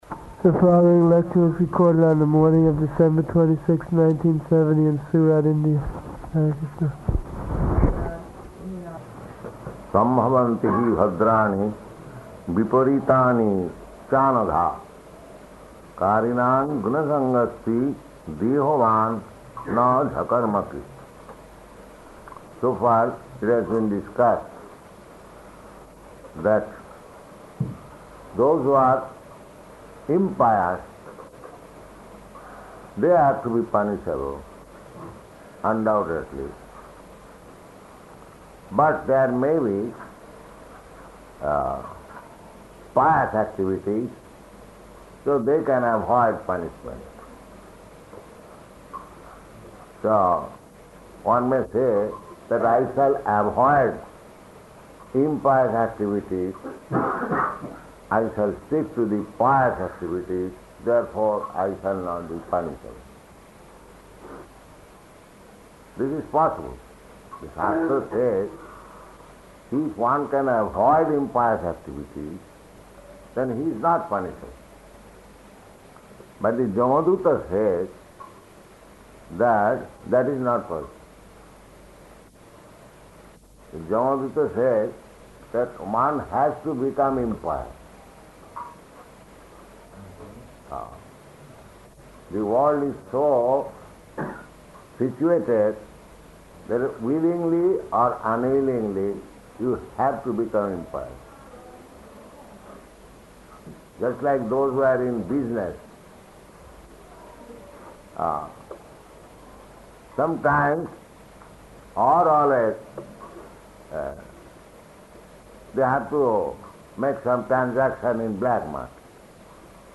Type: Srimad-Bhagavatam
Location: Surat